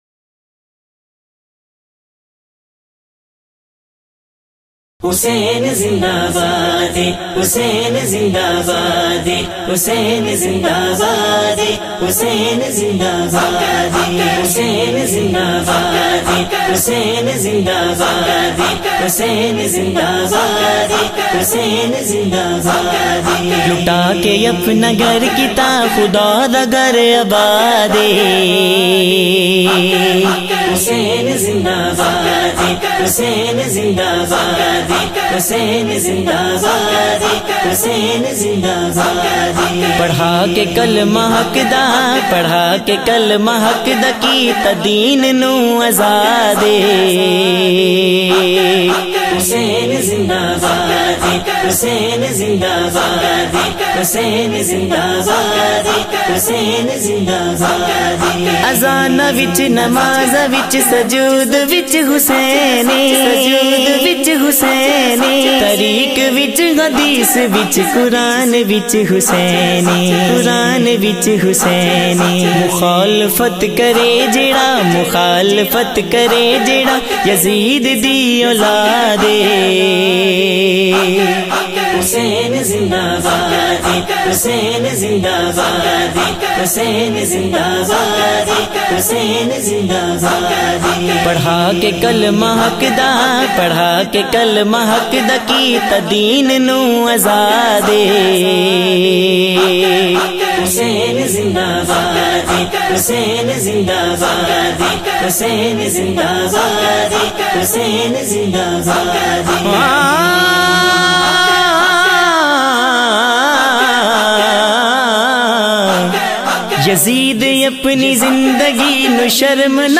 kalam
in a Heart-Touching Voice